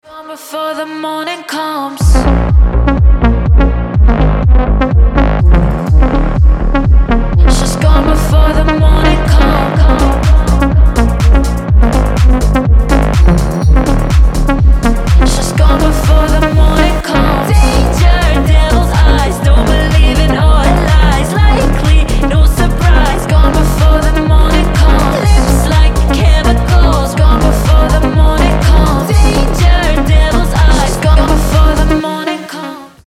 deep house
атмосферные
женский голос